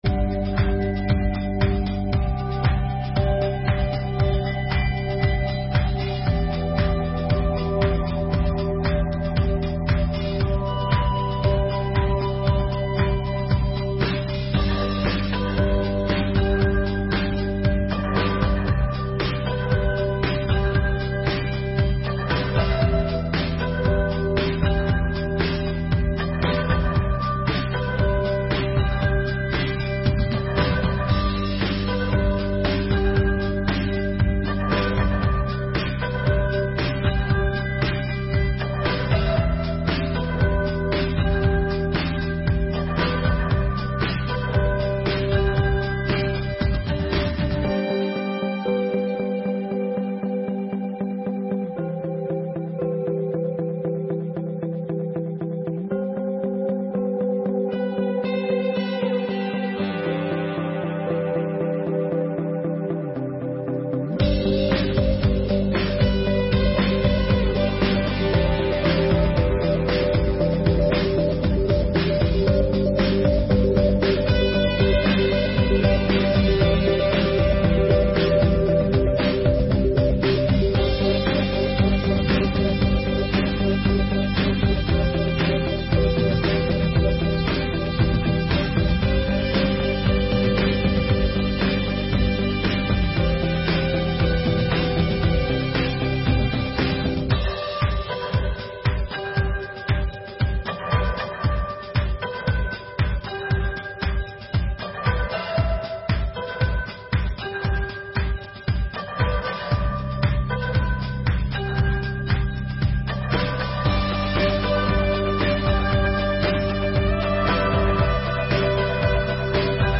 7ª Sessão Ordinária de 2023